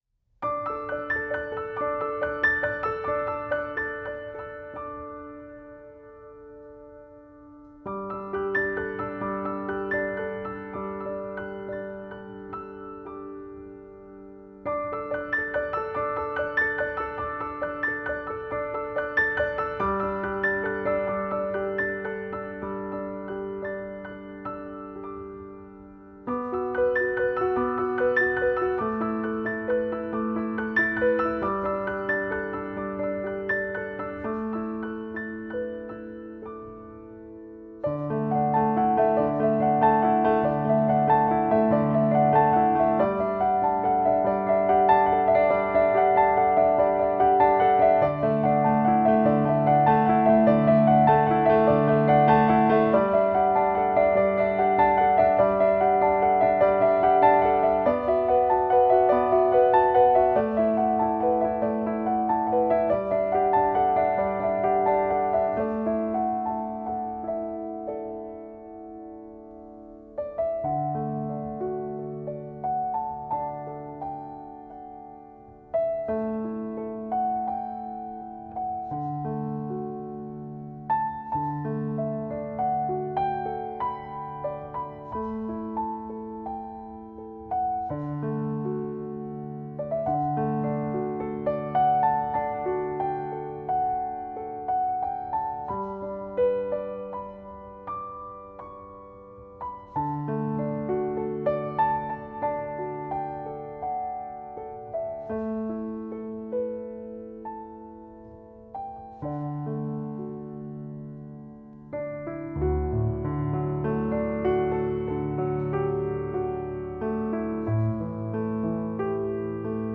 音乐风格: New Age / Piano / Classical